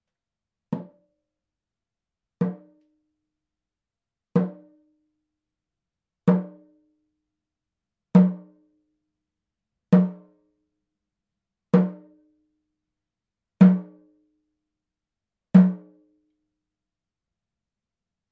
ネイティブ アメリカン（インディアン）ドラム NATIVE AMERICAN (INDIAN) DRUM 12インチ（elk アメリカアカシカ・ワピチ）
ネイティブアメリカン インディアン ドラムの音を聴く
乾いた張り気味の音です 温度・湿度により皮の張り（音程）が大きく変化します